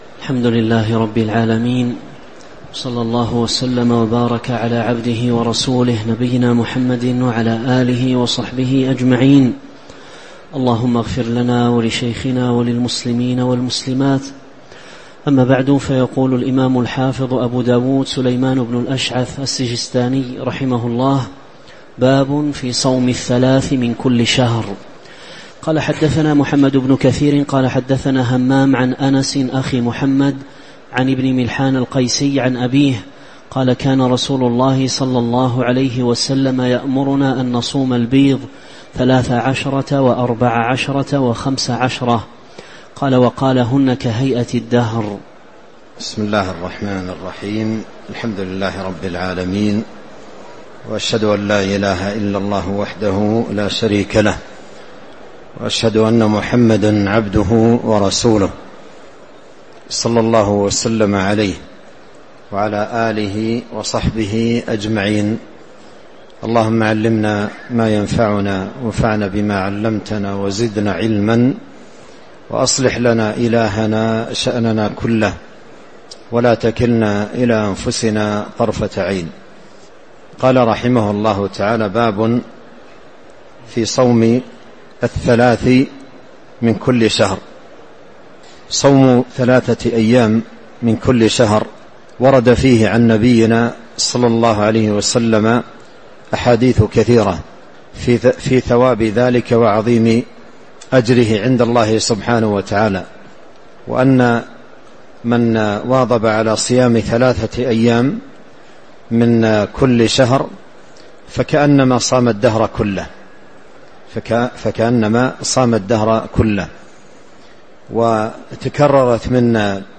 تاريخ النشر ٢٠ رمضان ١٤٤٦ هـ المكان: المسجد النبوي الشيخ